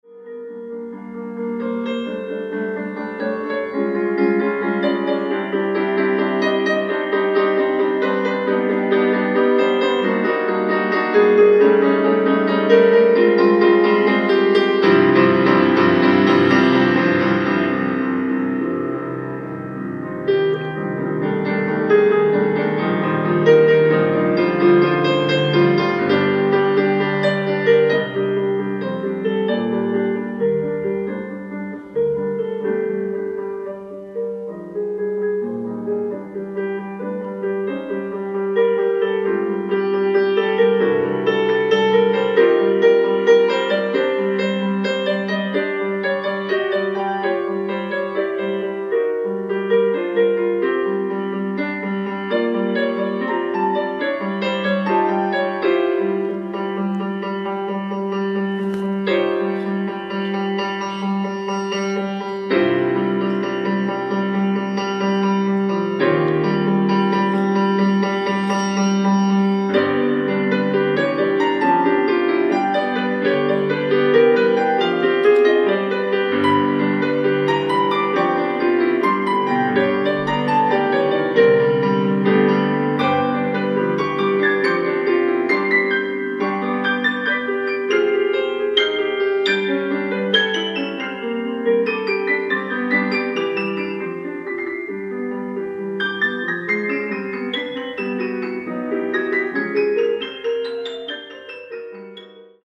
ライブ・アット・ＯＲＧトリノ、トリノ、イタリア 02/16/2018
※試聴用に実際より音質を落としています。